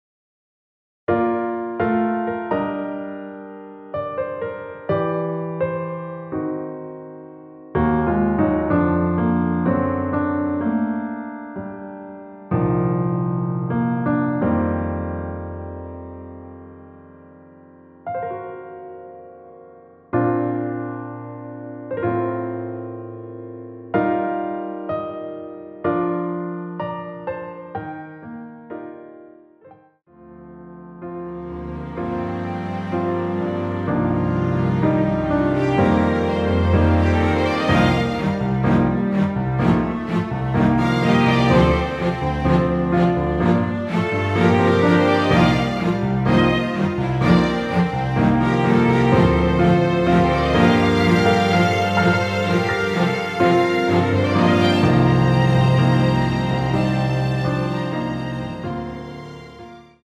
원키에서(-3)내린 MR입니다.
Bb
앞부분30초, 뒷부분30초씩 편집해서 올려 드리고 있습니다.
중간에 음이 끈어지고 다시 나오는 이유는